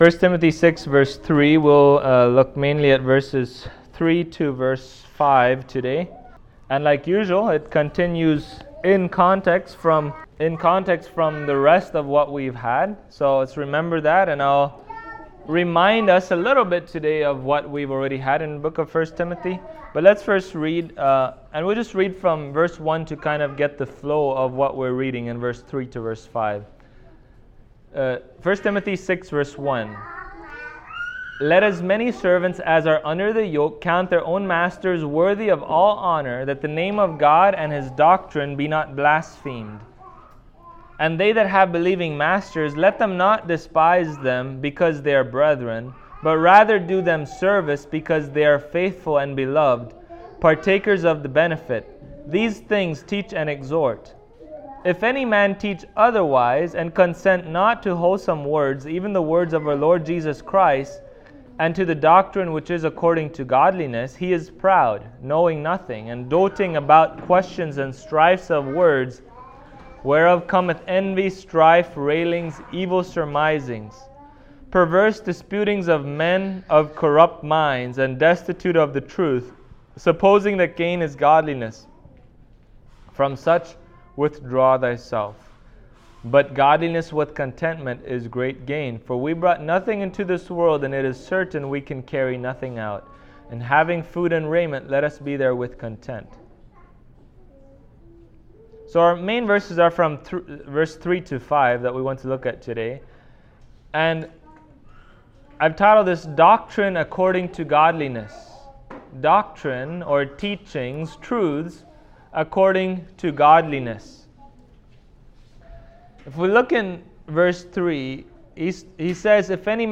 1Tim 6:3-5 Service Type: Sunday Morning Sound doctrine is essential for every Christian.